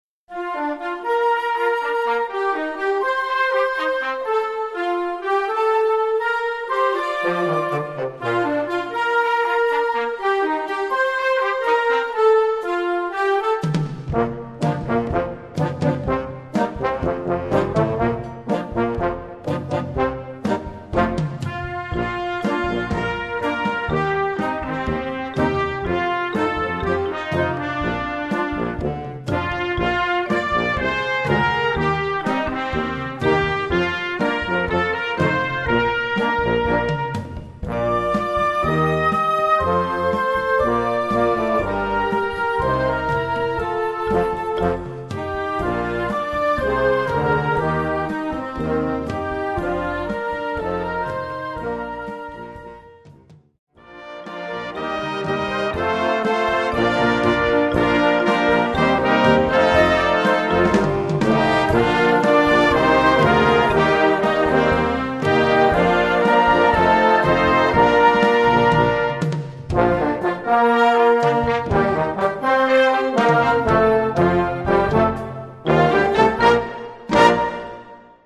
Gattung: A Christmas Calypso
Besetzung: Blasorchester